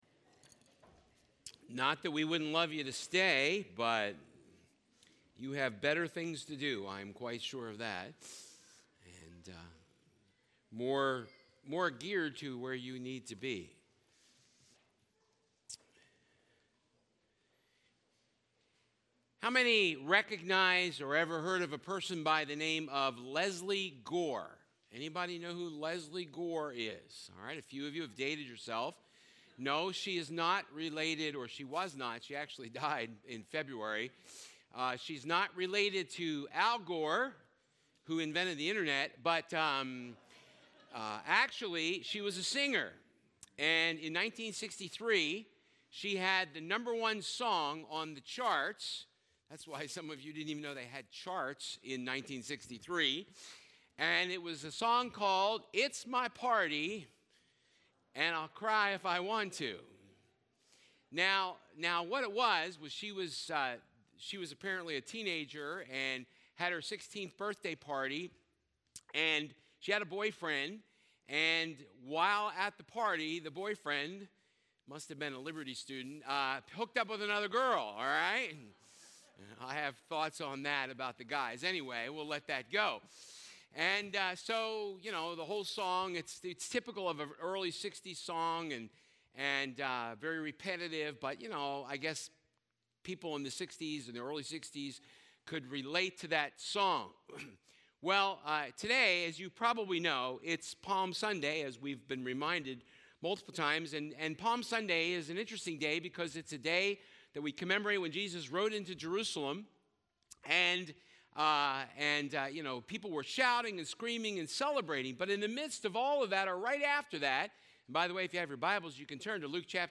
Luke 19:1 Service Type: Sunday Service It's my party and I'll weep if I want to.